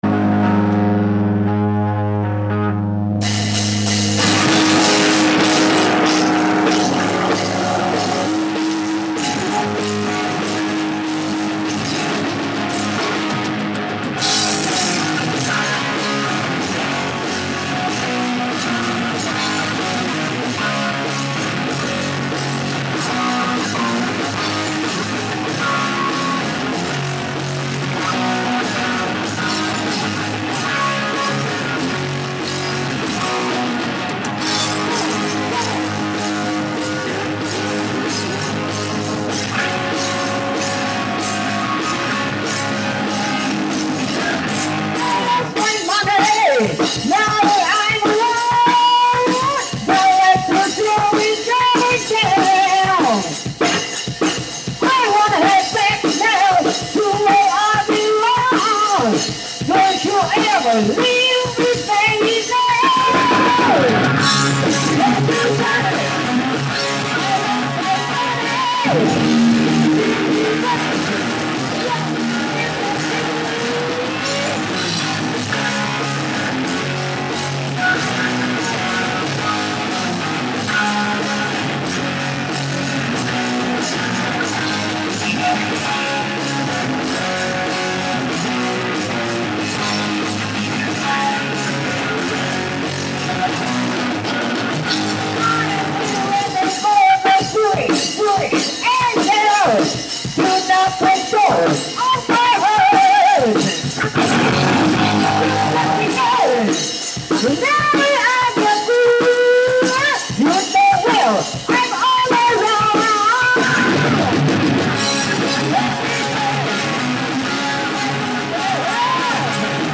Practice at the 3.19 studio (Take me Back)
スタジオだから音は悪いよ。